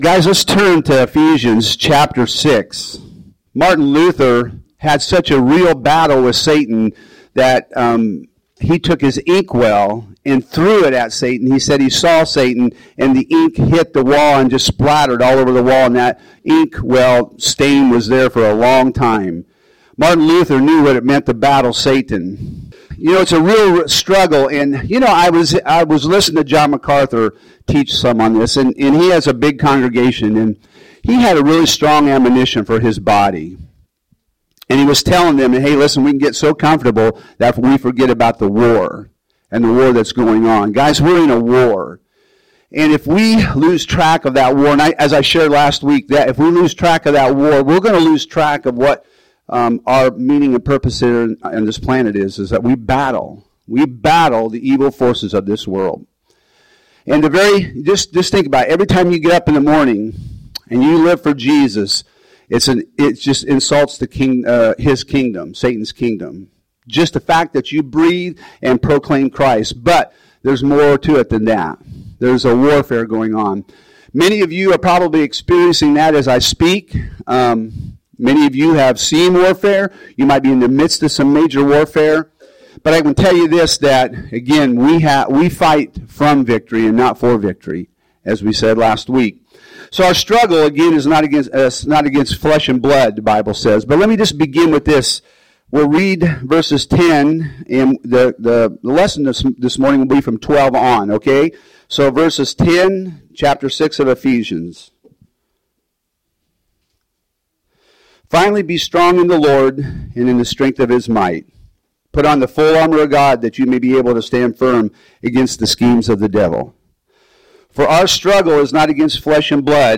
Home › Sermons › Ephesians 6:10~24